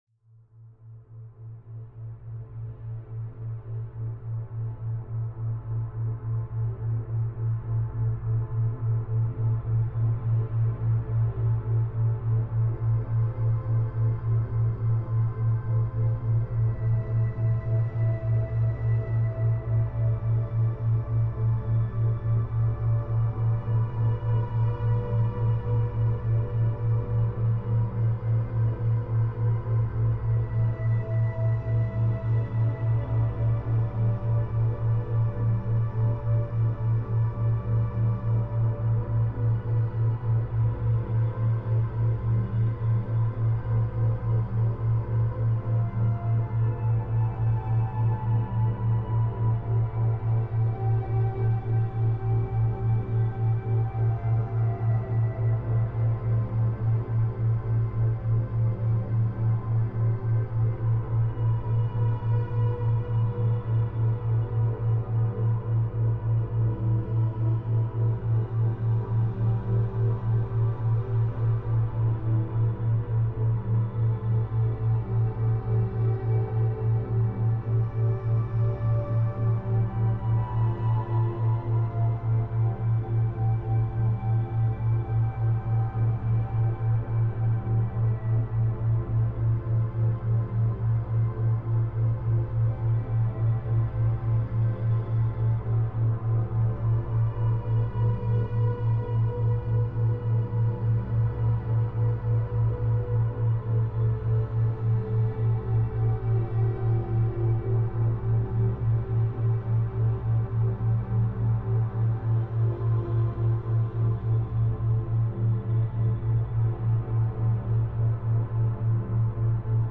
Searching for the perfect background sound to elevate your concentration and motivation?
Background Sounds